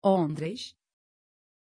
Pronuncia di Ondrej
pronunciation-ondrej-tr.mp3